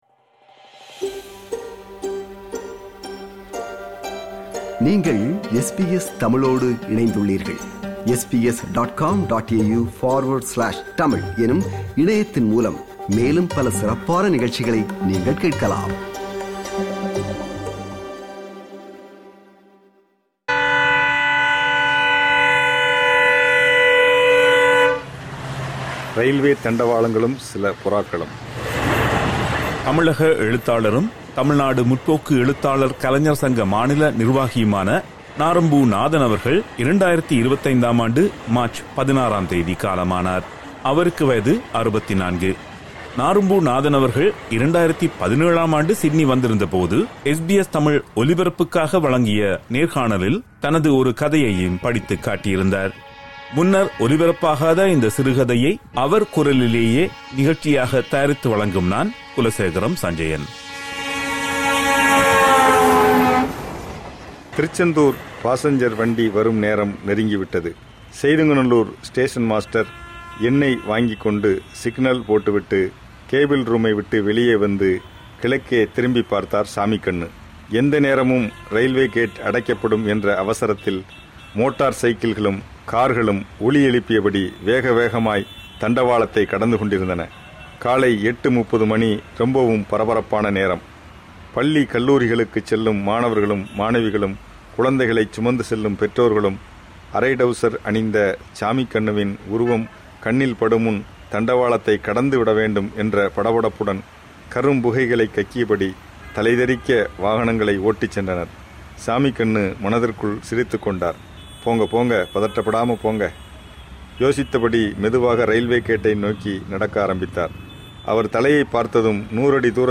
அந்த நேர்காணலின் போது, தனது சிறுகதை ஒன்றை அவரது குரலிலேயே அவர் வாசித்திருந்தார். அதனை ஒரு நிகழ்ச்சியாகத் தயாரித்து வழங்குகிறோம்.